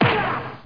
1 channel
ricocett.mp3